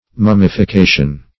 Mummification \Mum`mi*fi*ca"tion\, n. [See Mummify.]